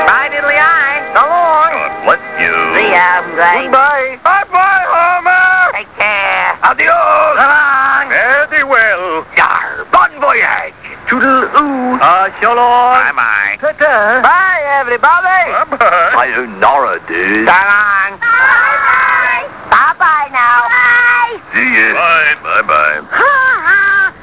byebye Good 4 when you're closing your computer down but it was obviously dubbed off the radio or TV